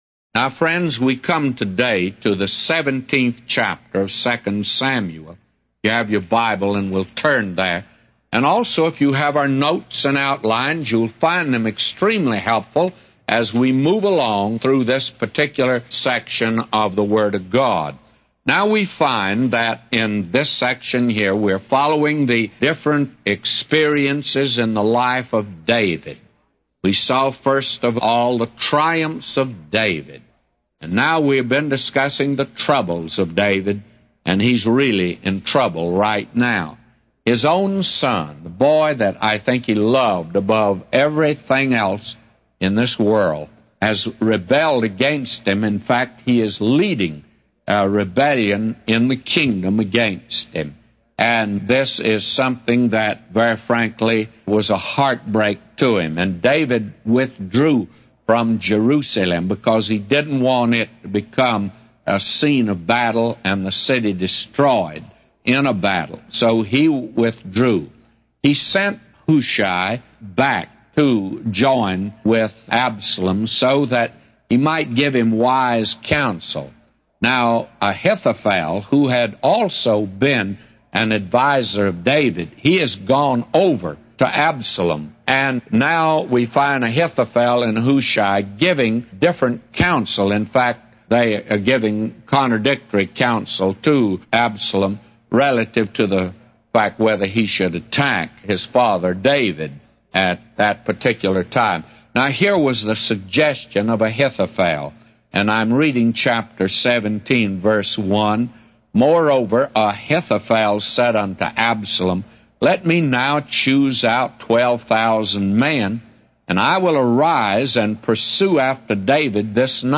A Commentary By J Vernon MCgee For 2 Samuel 17:1-999